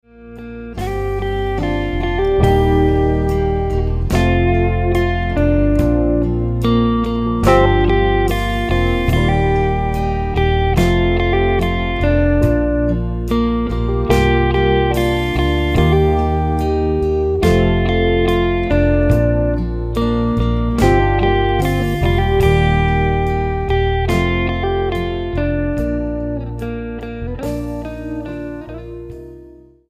STYLE: MOR / Soft Pop
A relaxing and soothing selection of 14 instrumental tracks
acoustic and electric guitars